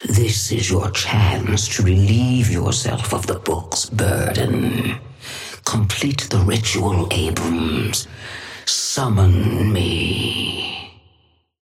Sapphire Flame voice line - This is your chance to relieve yourself of the book's burden. Complete the ritual, Abrams. Summon me.
Patron_female_ally_atlas_start_02.mp3